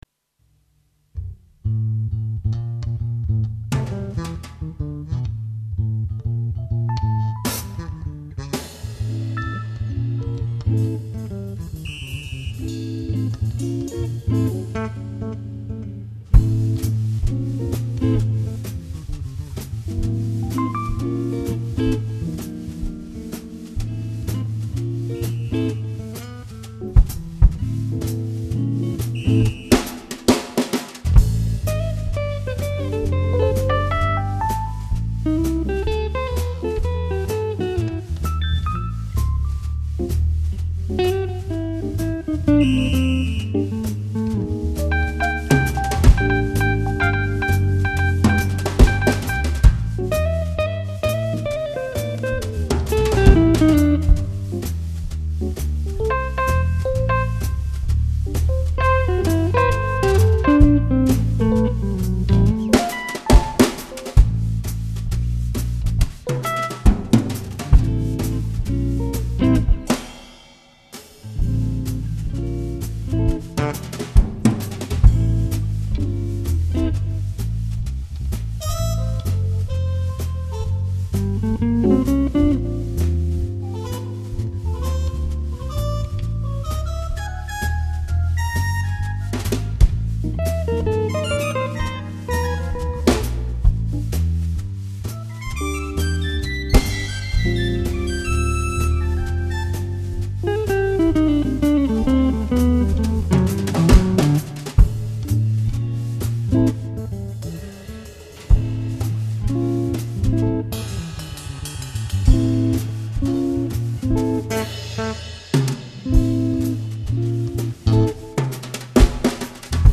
Wir haben hier einfach mal 2 mehr oder weniger spontane Einfälle zum Test unseres neuen Mini-Studio Equipment auf 2 Spuren aufgenommen.
Holperig, aber für nen spontanen Equipmentprobelauf ganz ok und deshalb für meinen Beitrag geeignet. Aufgenommen wurde das Ganze über Cubase auf 2 Spuren ohne besondere Einstellungen.
zu 2: Klingt echt entspannend, so zum nebenher laufen lassen!
Der zweite Song ist echt geil, schön "entspannend"...